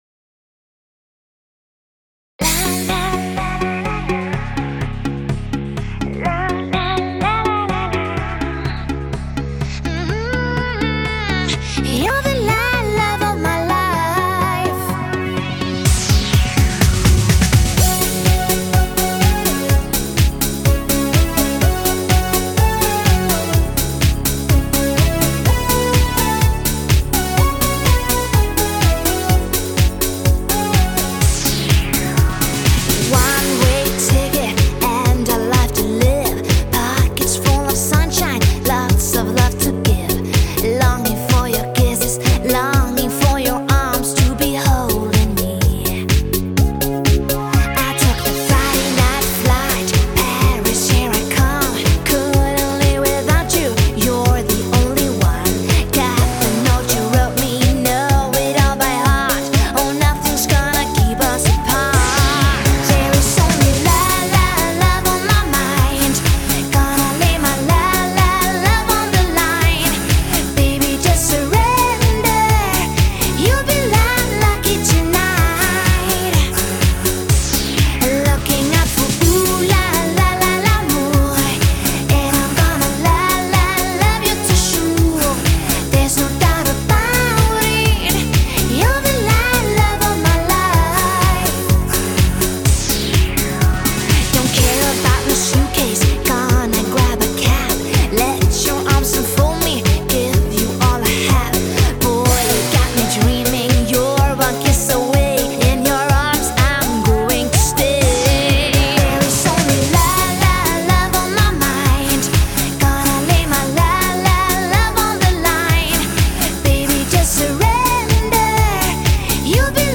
加上欧美最迷幻时尚的舞曲，编配超强动感和节奏，
劲爆无敌，至嗨至疯狂的特色，引领着全新热舞狂潮。
火爆的现场感，清晰保真的男女声演唱，专业的MC喊麦
，畅快淋漓的Rap说唱，电子乐、打击乐层次分明，就是为了告诉你，